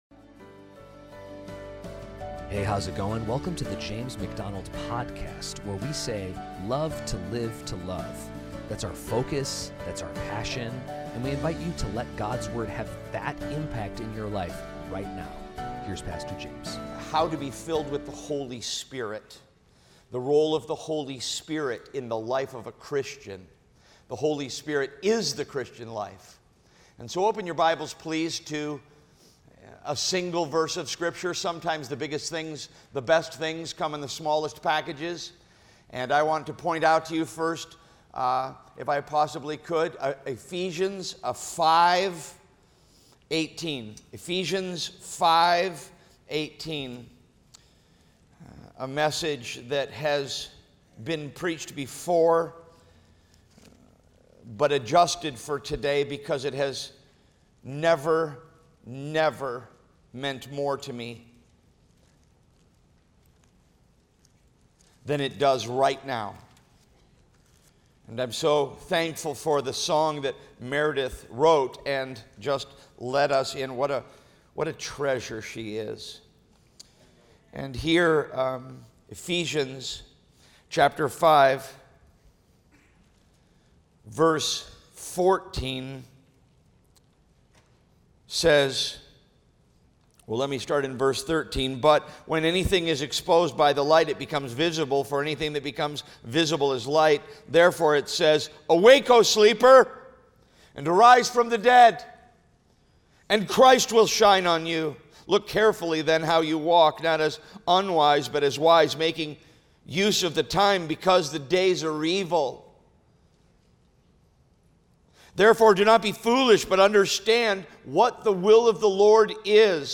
preaches without apology